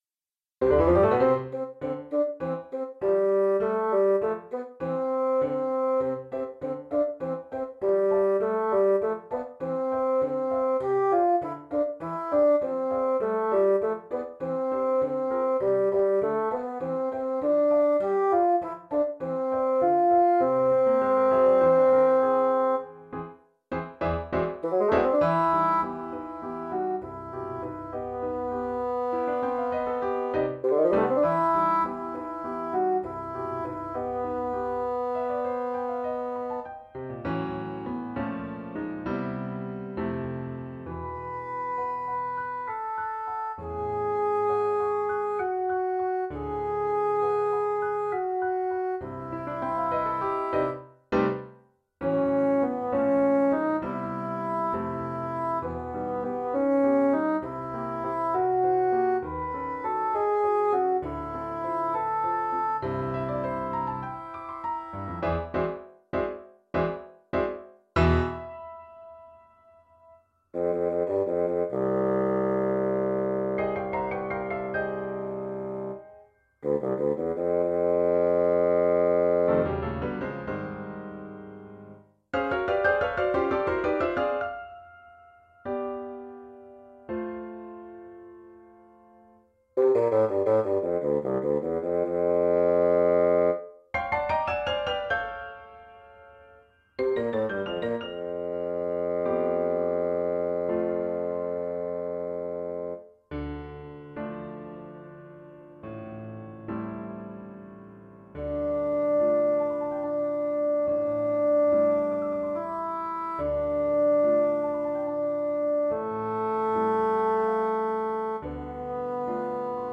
Bassoon